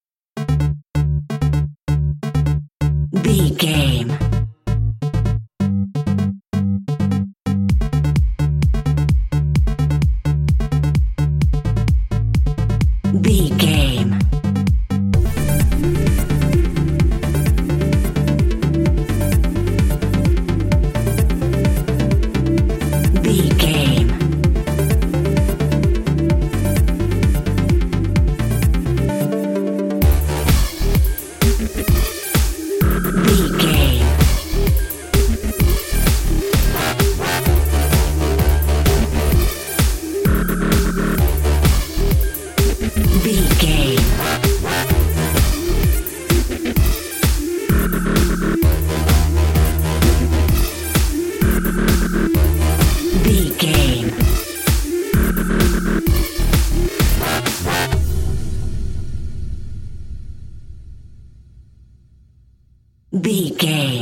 Aeolian/Minor
Fast
aggressive
dark
groovy
futuristic
frantic
drum machine
synthesiser
breakbeat
energetic
synth leads
synth bass